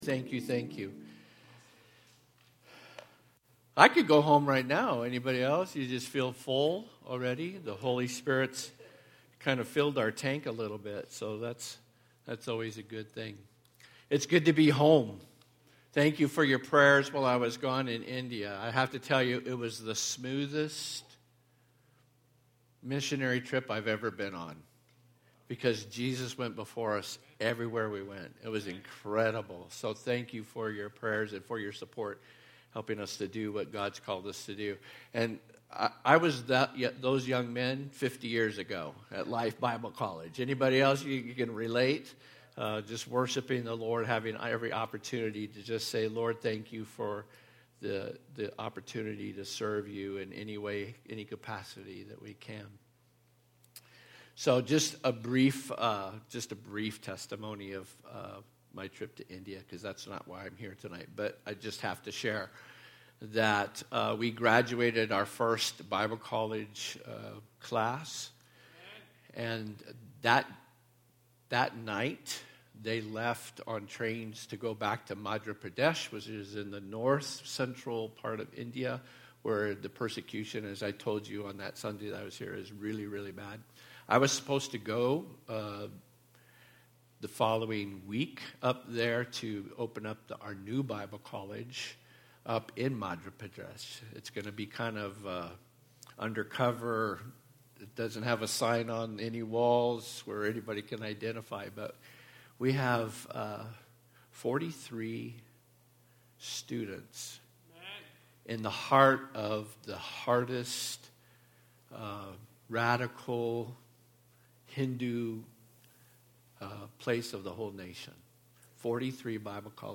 Saturday morning session of the Forged Men's Conference 2025